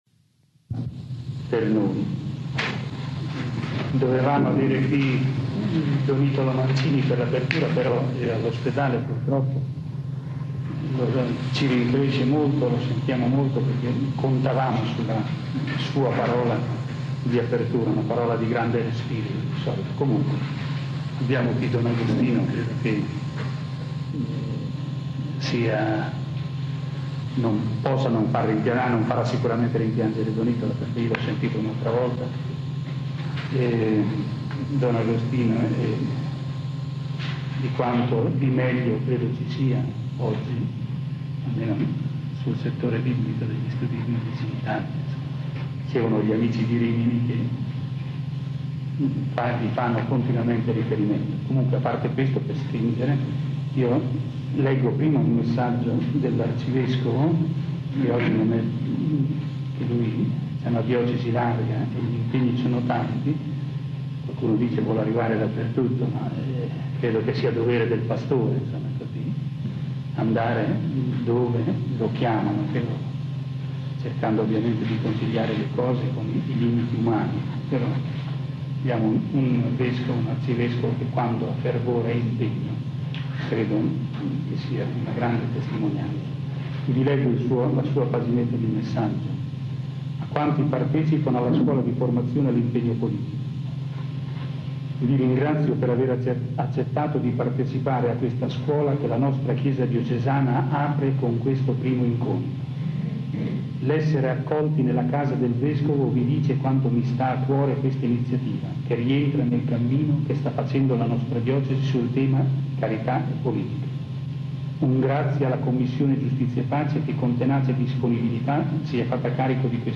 Prolusione
Contributi audio - registrazioni delle lezioni